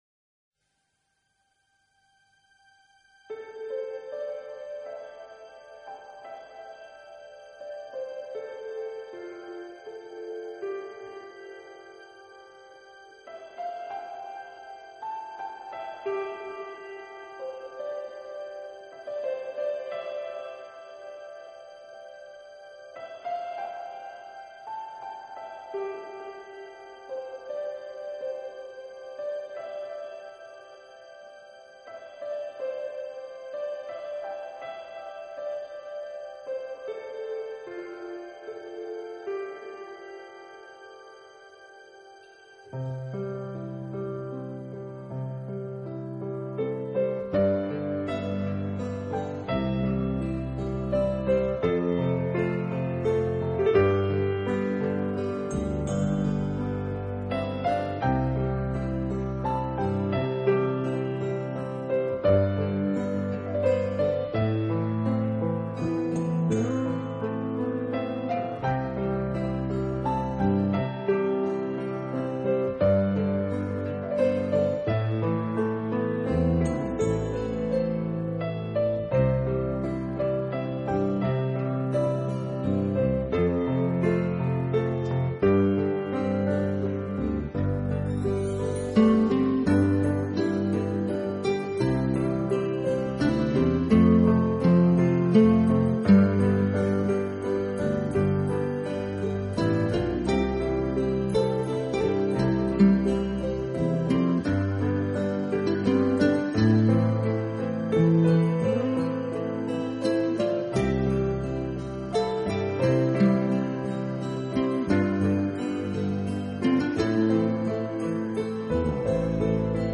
音乐类型：New Age
他的钢琴，简单乾净，